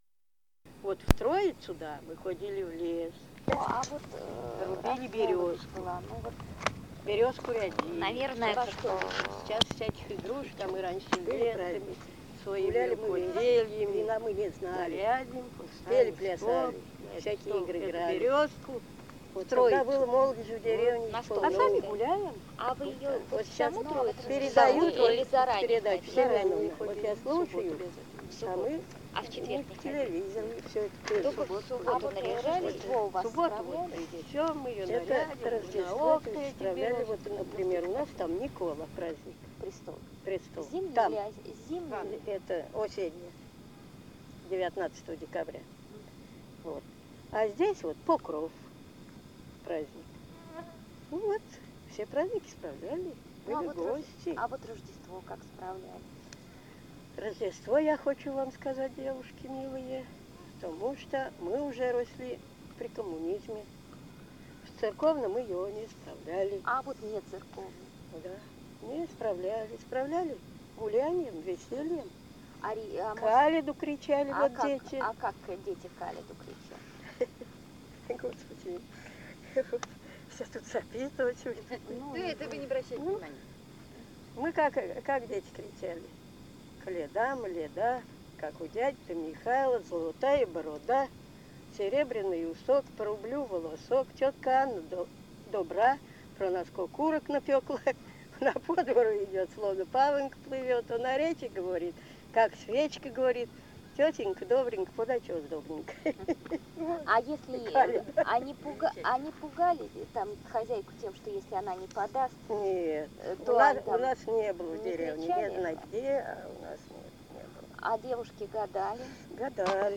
Место фиксации: Владимирская область, Гороховецкий район, деревня Чудская